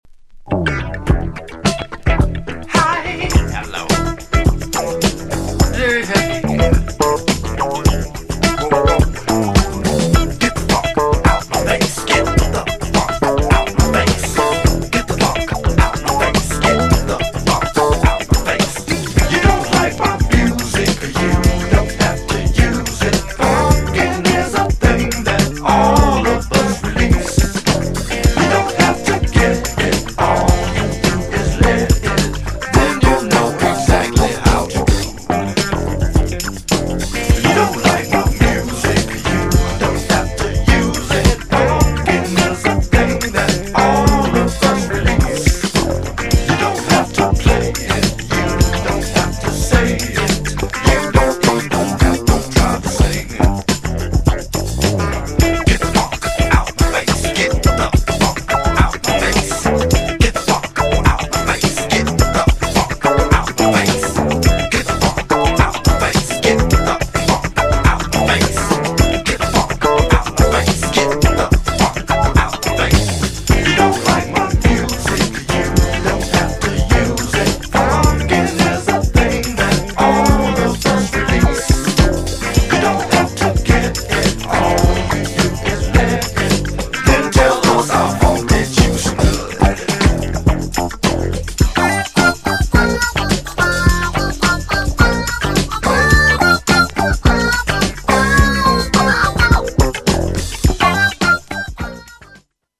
GENRE Dance Classic
BPM 96〜100BPM